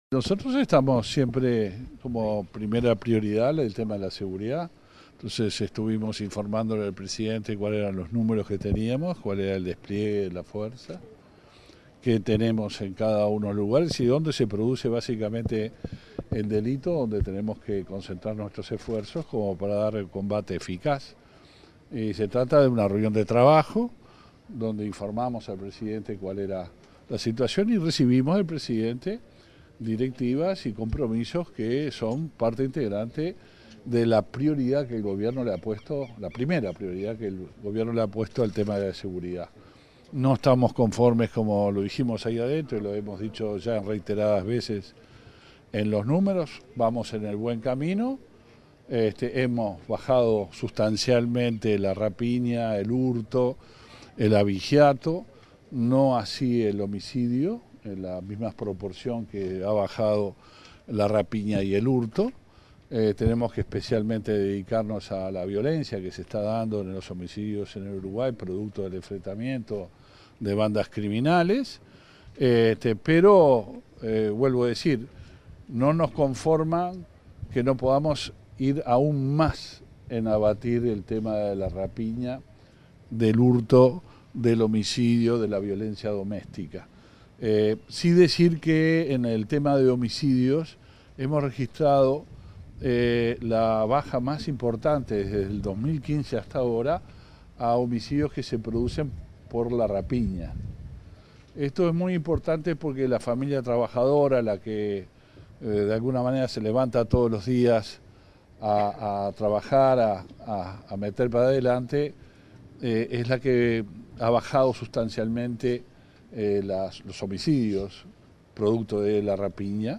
Declaraciones a la prensa del ministro del Interior, Luis Alberto Heber
Declaraciones a la prensa del ministro del Interior, Luis Alberto Heber 30/03/2023 Compartir Facebook X Copiar enlace WhatsApp LinkedIn Tras participar en la ceremonia de egreso de agentes de policía, este 30 de marzo, el ministro del Interior, Luis Alberto Heber, realizó declaraciones a la prensa.